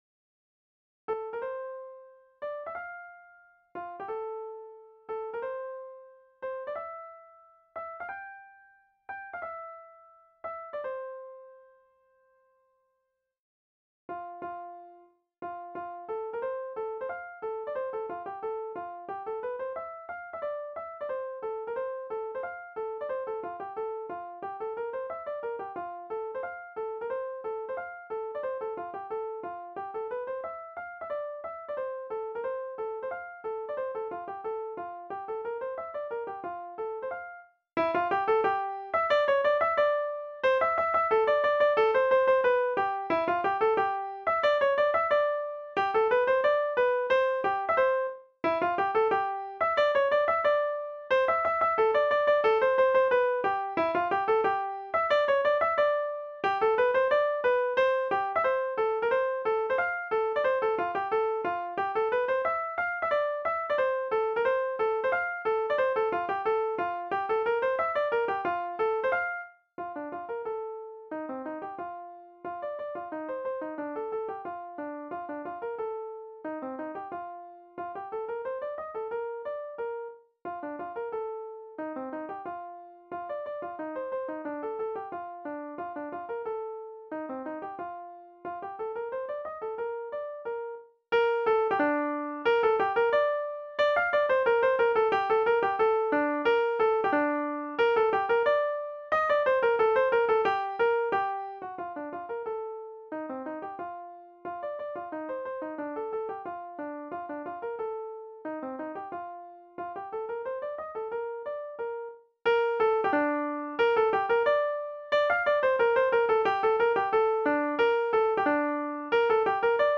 Localisation Curzon ( Plus d'informations sur Wikipedia ) Vendée
danse : scottich trois pas